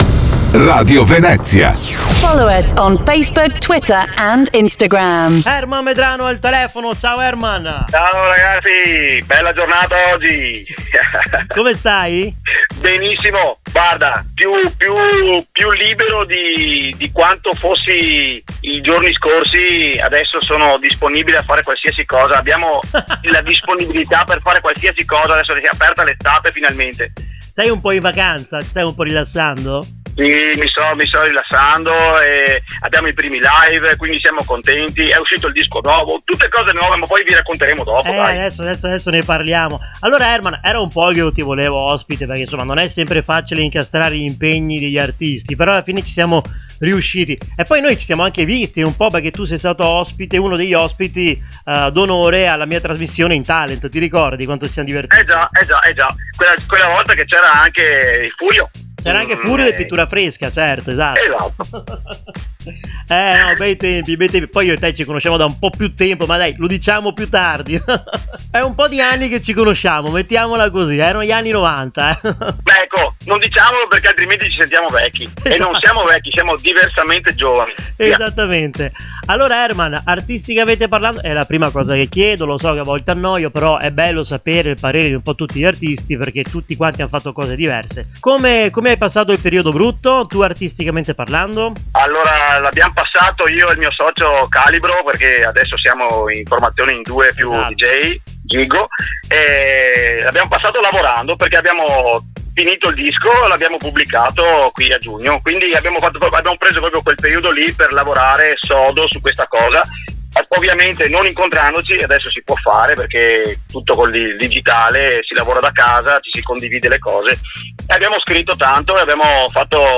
racconta con la voce carica di energia ed entusiasmo